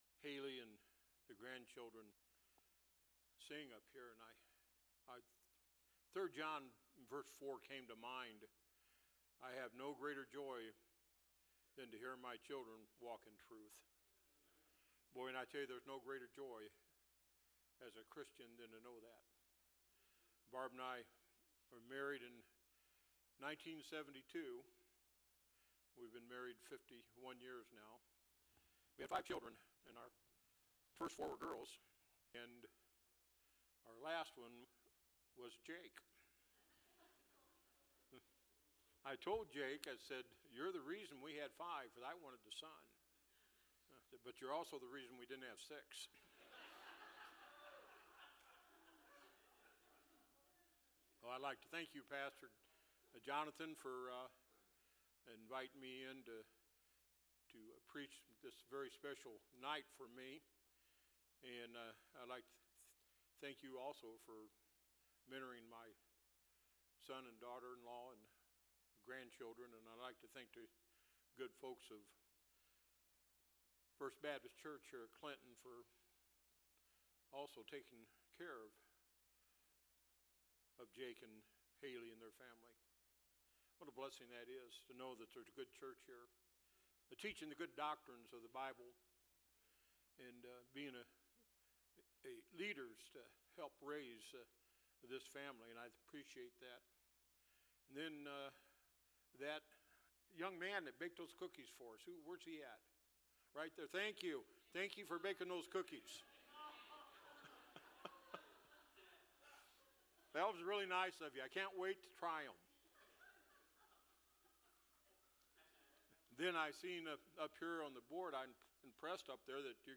Preaching from the Pulpit | First Baptist Church